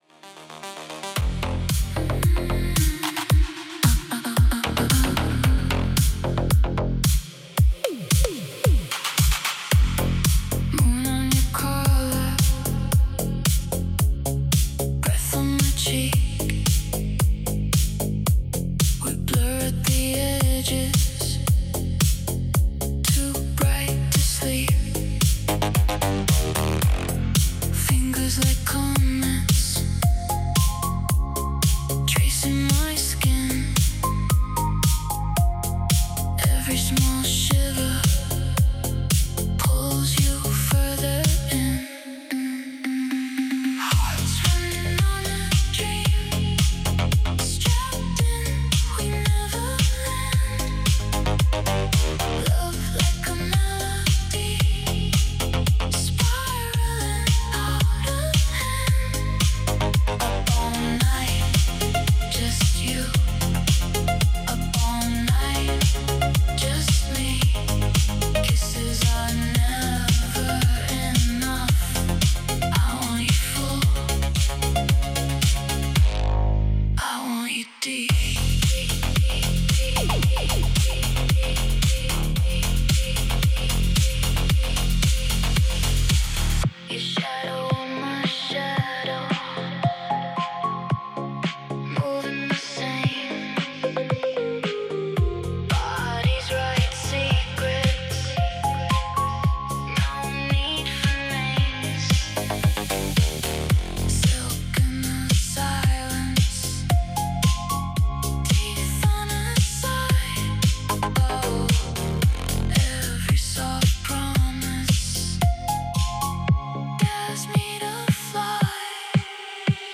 a young woman singing about her love night-adventure